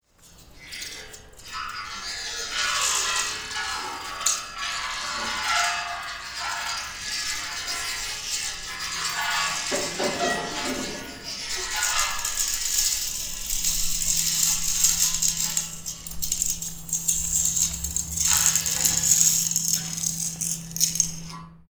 Metal Wire
dragging fx hit industrial iron long metal metallic sound effect free sound royalty free Memes